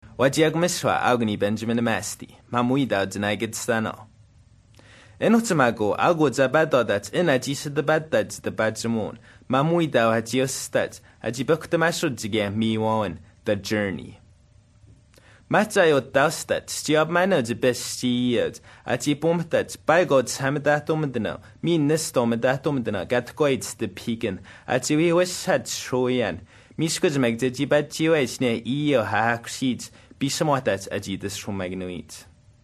A lot of sharp fricatives, like Polish; one retroflexed trill as in american english (that jerk),”de phi:gen” sounds scandinavian,the word Thibet. long vowels, sharp articulated as in baltic tangues…